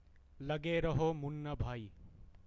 LageRahoMunnaBhaiPronounciation.ogg.mp3